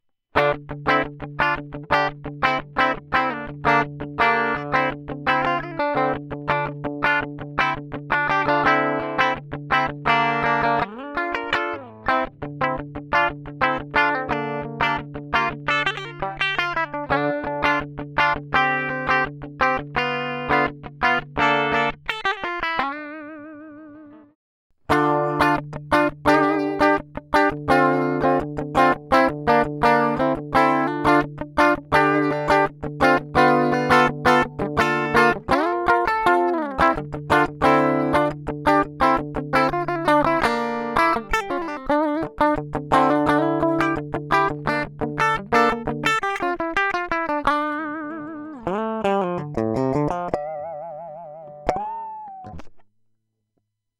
Записано в звуковуху, сначала пассив, потом актив. В актив-гитару, (гитара с пассивами), встроен бустер на полевике.